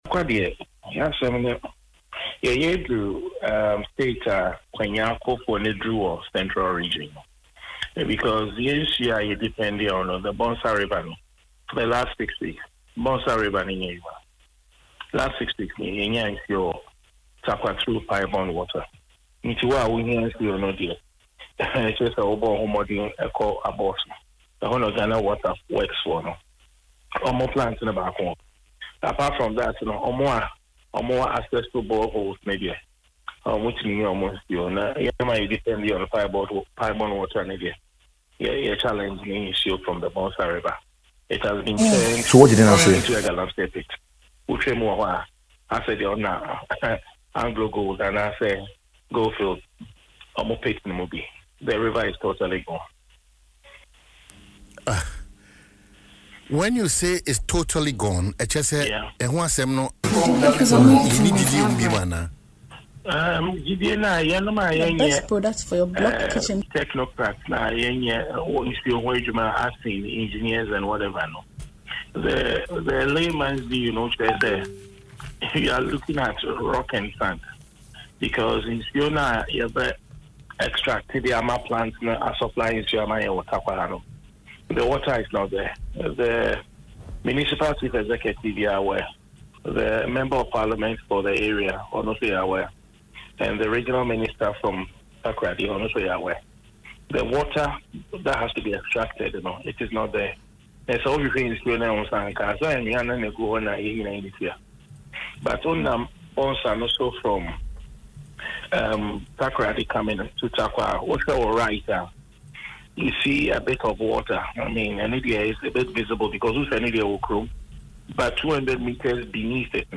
Speaking in an interview on Adom FM’s Dwaso Nsem